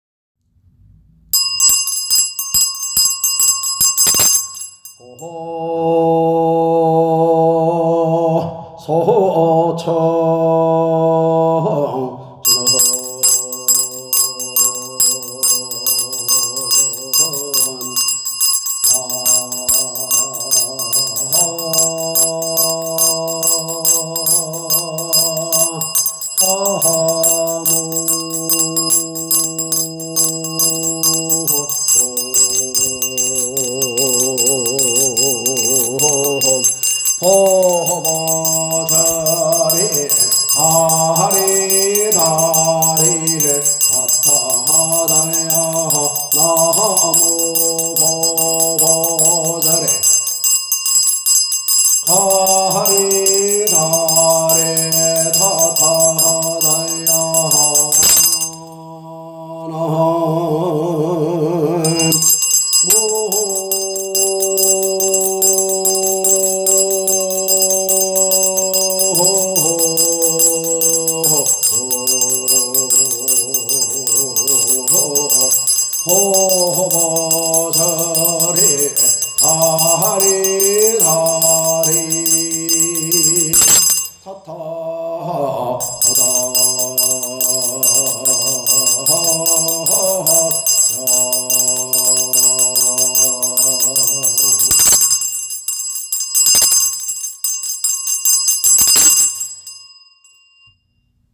보소청진언 "나무 보보제리 가리다리 다타 아다야"(3번) 보소청진언은 상단불공, 중단불공 또는 각종 재의식에서 불보살 제신들을 널리 불러 초청하는 진언이다. 법주가 혼자서 요령을 저으며 한다. 또는 각종사물과 함께 하는 경우는 시식편에서 빠르게 염송을 하면서 하게 된다.
음원의 상태가 조금 좋지 않아도 도움이 되신다면 다운받으셔도 됩니다.